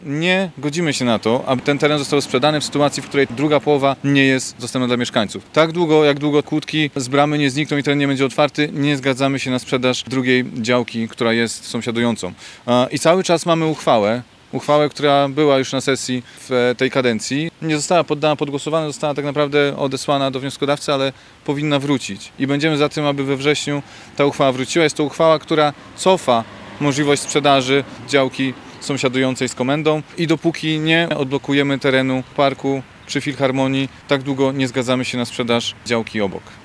W tej sprawie radni zwołali konferencję prasową i zapowiadają zablokowanie przetargu na sprzedaż jednej z działek.